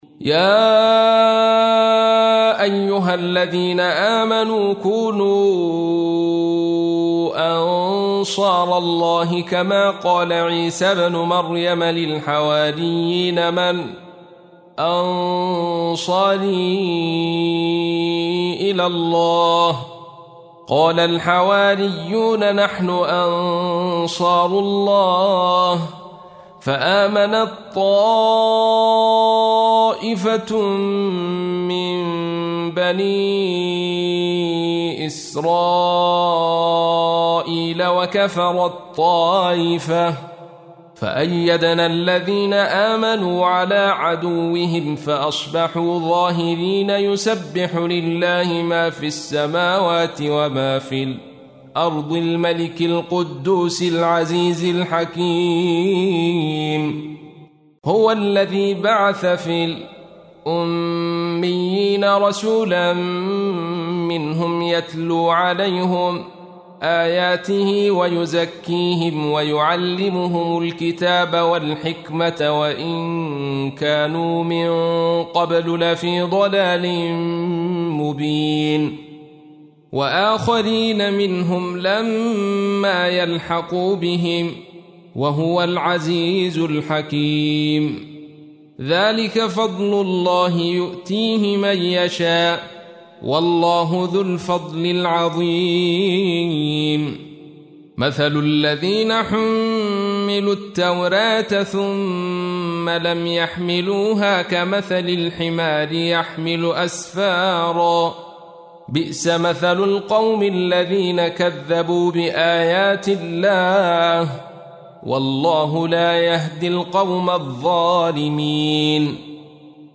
تحميل : 62. سورة الجمعة / القارئ عبد الرشيد صوفي / القرآن الكريم / موقع يا حسين